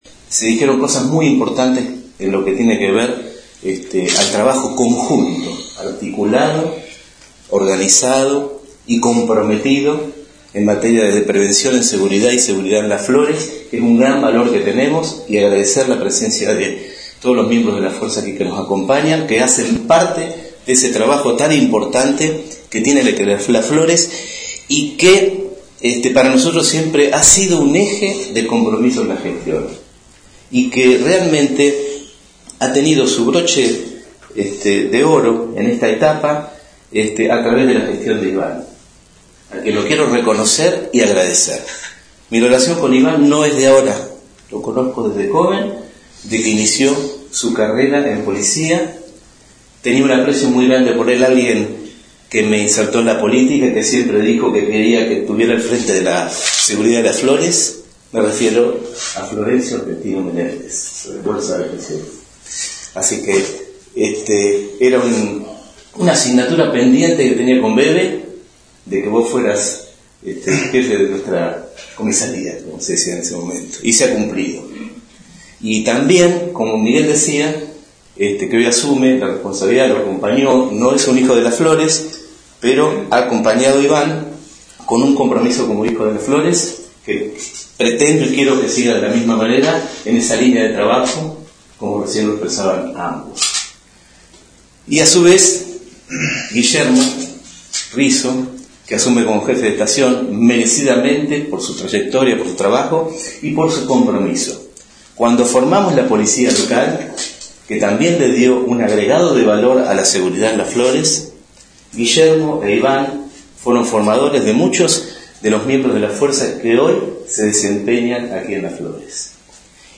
Audio completo del intendente Gelené
Gelene-acto-cambio-policial.mp3